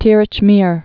(tîrĭch mîr)